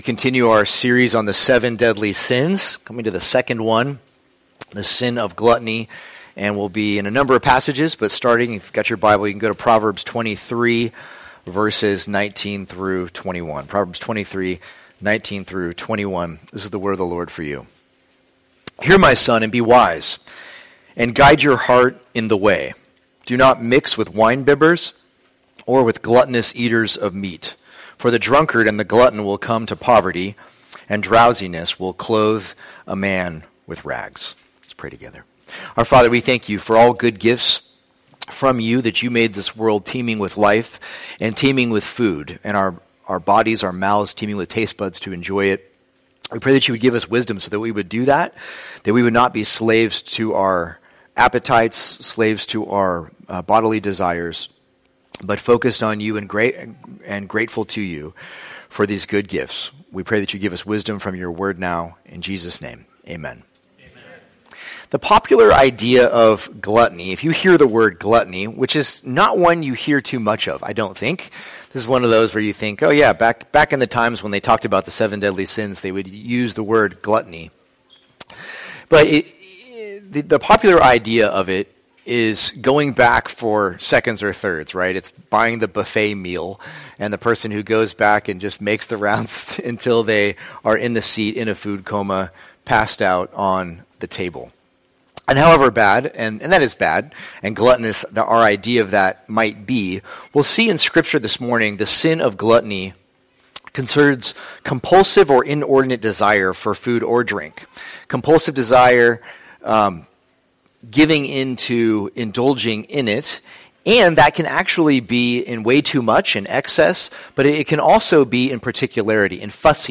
Gluttony Preacher